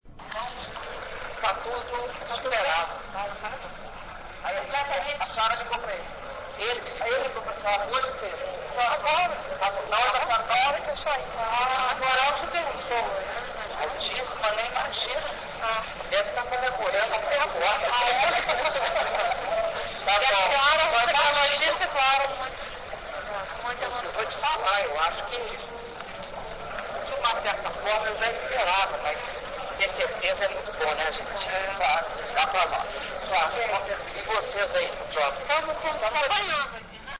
Entrevista coletiva concedida pela Presidenta da República, Dilma Rousseff - Nova Délhi/Índia (37s)